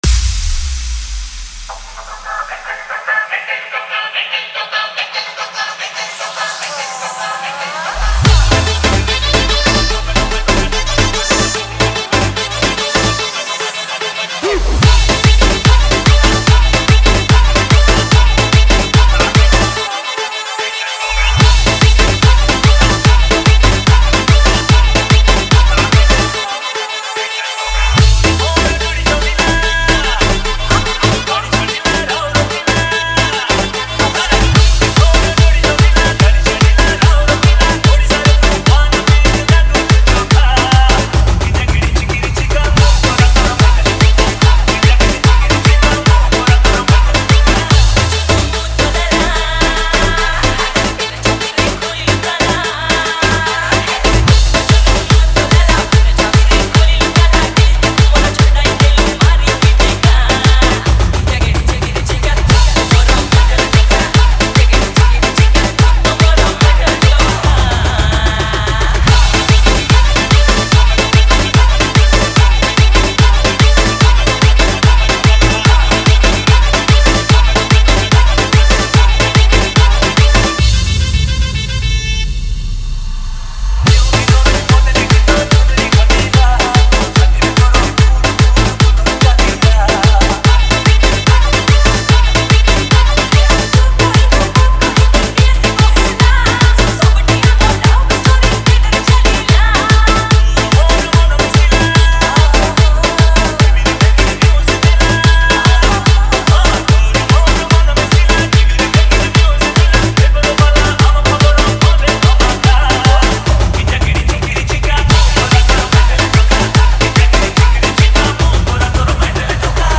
DANCE FLEVOUR